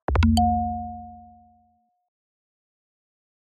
knock-knock.mp3